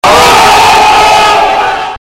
Play, download and share SupaHot(Distortion) original sound button!!!!
supahot-distortion.mp3